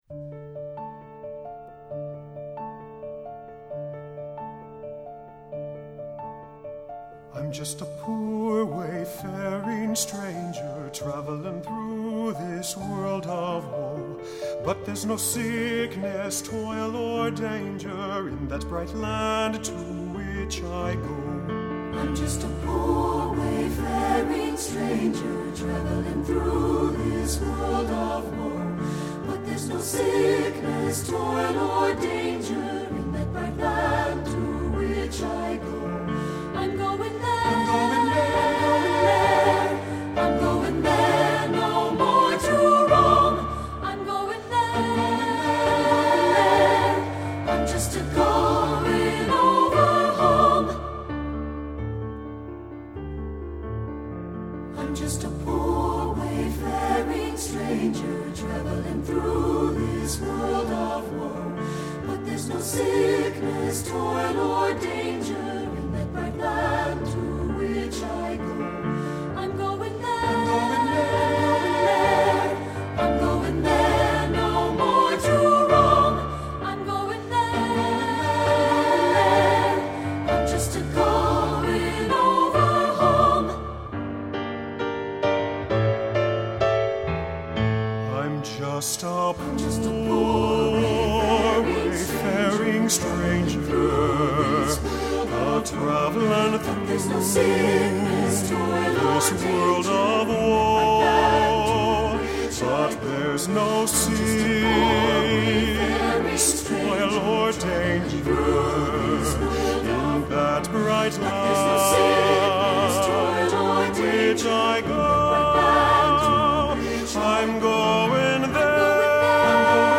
Studio Recording
Ensemble: Three-part Mixed Chorus
Key: D minor
Accompanied: Accompanied Chorus